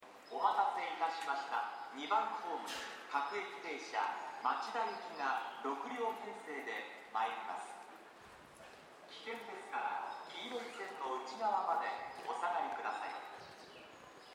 この駅では接近放送が設置されています。
２番ホームOE：小田急江ノ島線
接近放送各駅停車　町田行き接近放送です。